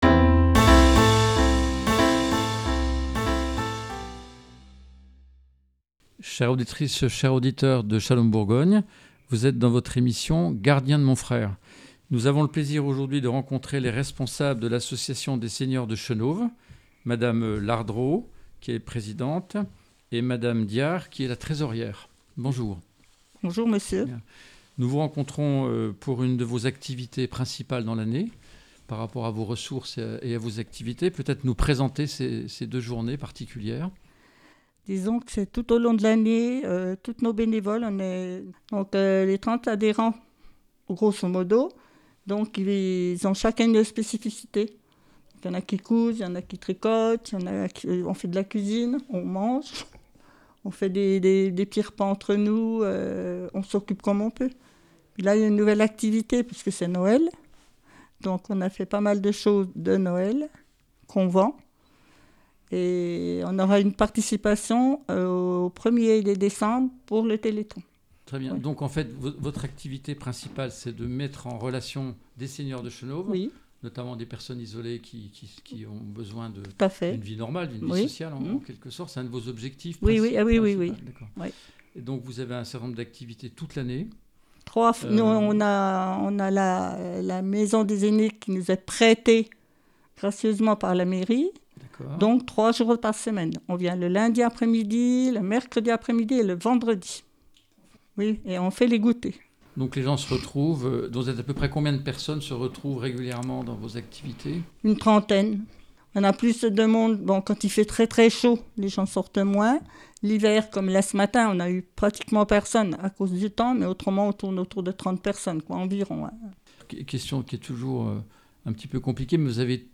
29 novembre 2024 Écouter le podcast Télécharger le podcast Le 22 novembre 2024, "Shalom Bourgogne" s'est rendue à la Maison des Aînés du CCAS de Chenôve pour y rencontrer l'Association des Séniors de Chenôve qui vendait des objets confectionnés par les bénévoles de l'association à son profit et, notamment, pour un don au Téléthon.